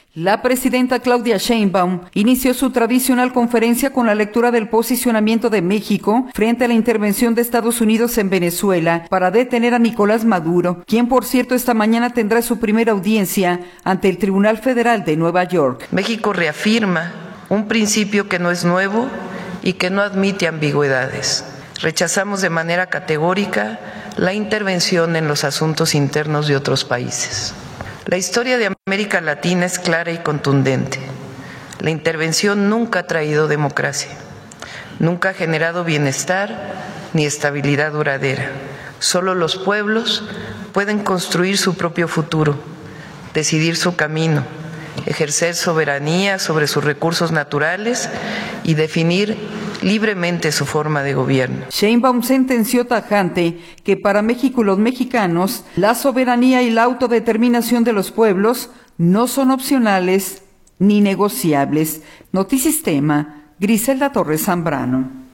La presidenta Claudia Sheinbaum inició su tradicional conferencia, con la lectura del posicionamiento de México frente a la intervención de Estados Unidos en Venezuela, para detener a Nicolás Maduro, quien por cierto esta mañana tendrá su primera audiencia ante el Tribunal Federal […]